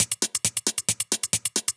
Index of /musicradar/ultimate-hihat-samples/135bpm
UHH_ElectroHatD_135-01.wav